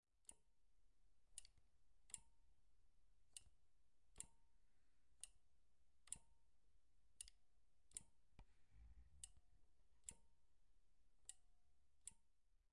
02 刹车